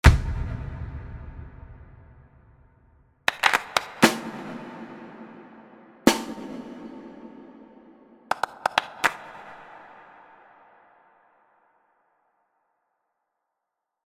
Um die Dichte, Transparenz und Auflösung der Raumsimulationen zu beurteilen, habe ich kurze Impulse, nämlich eine Bassdrum, eine Snare und Claps verwendet.
Beinahe identisch mit dem B2, jedoch eine Spur gleichmäßiger im Verlauf der Echocluster. Exzellent.